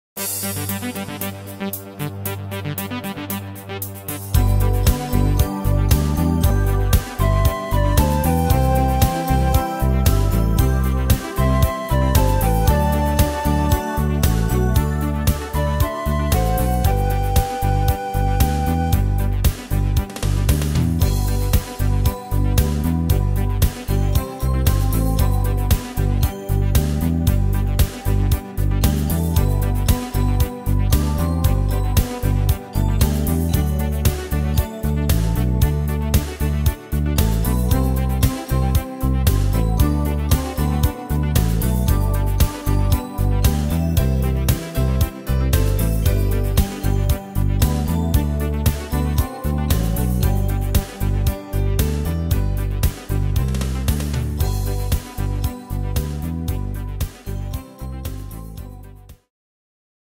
Tempo: 115 / Tonart: G-Dur